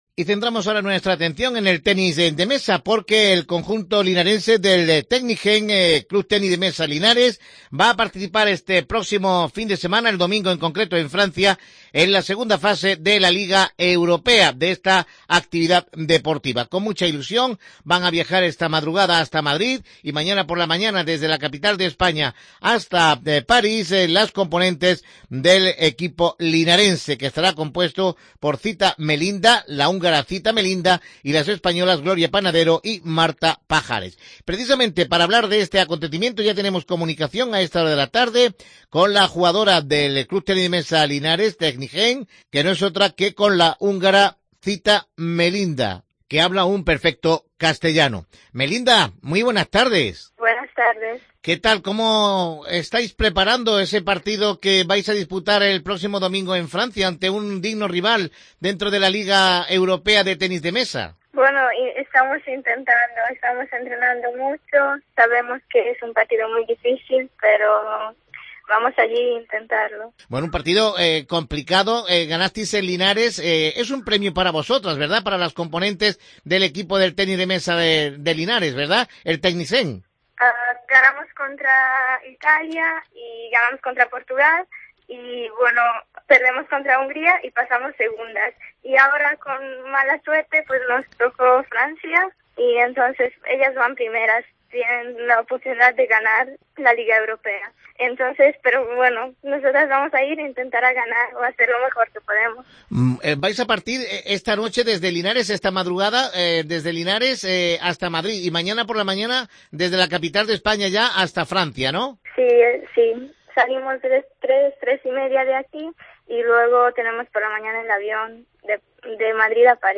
que ha intervenido en Deportes Cope, en charla con